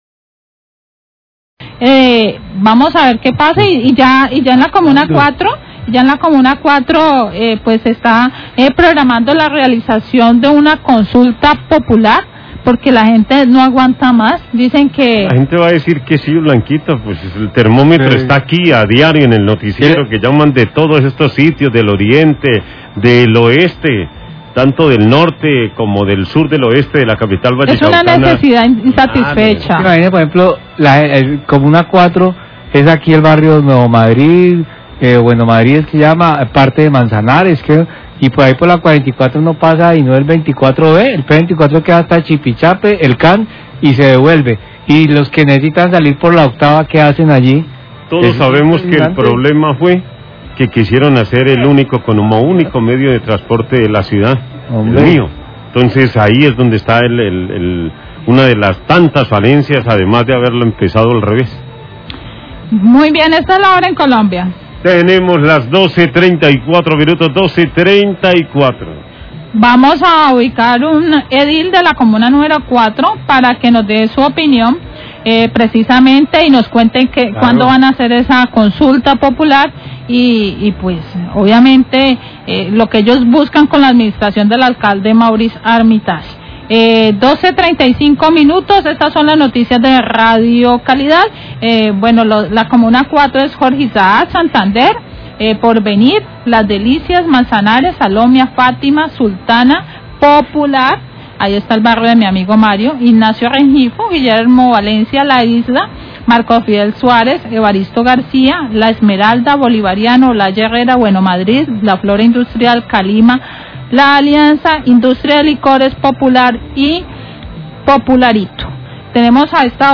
NOTICIAS DE CALIDAD
Los periodistas de la mesa opinan sobre la necesidad de que el transporte tradicional vuelva dada la falta de cobertura del MIO.